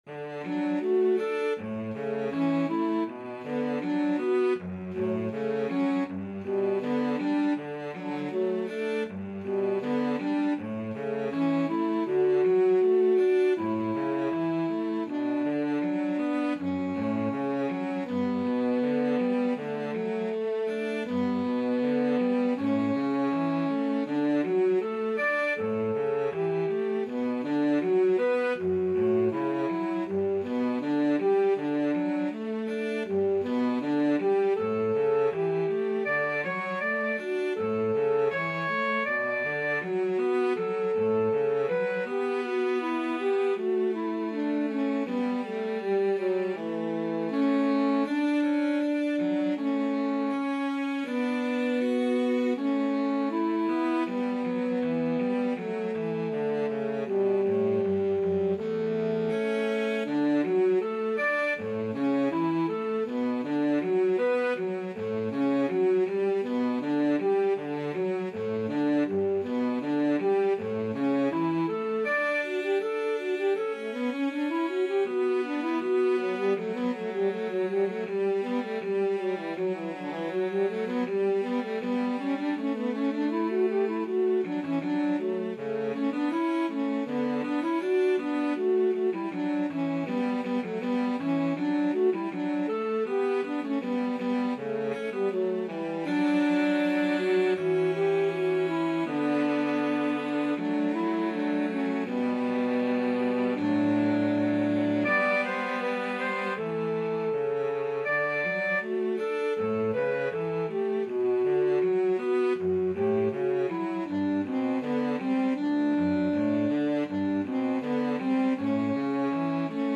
Alto SaxophoneCello